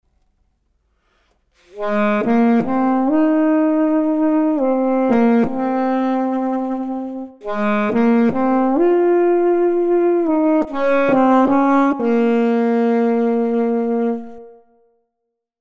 Sax, not clarinet.
For instance, if I play a little with the first few notes of that
little tuning.